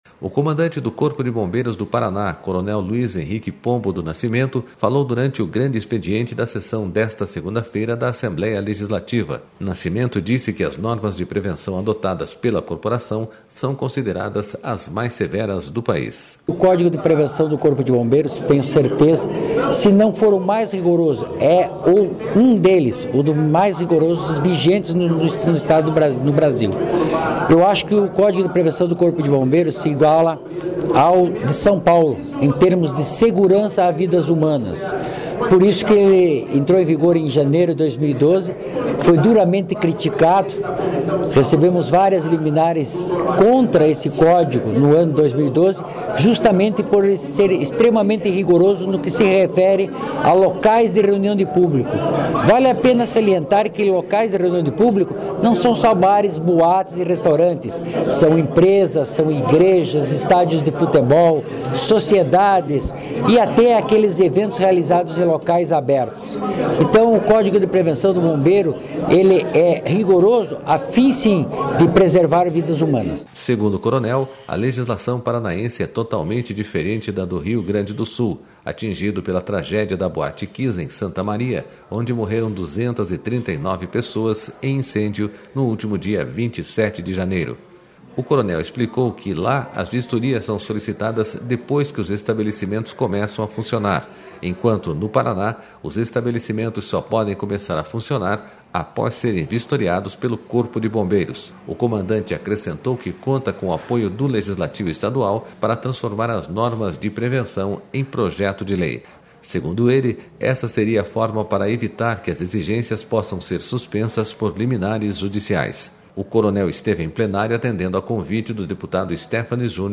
Comandante do Corpo de Bombeiros fala aos deputados sobre o trabalho de prevenção de incêndios
O comandante do Corpo de Bombeiros do Paraná, coronel Luiz Henrique Pombo do Nascimento, falou durante o Grande Expediente da sessão desta segunda-feira da Assembleia Legislativa.//Nascimento disse que as normas de prevenção adotadas pela corporação são consideradas as mais severas do país.//SONORA...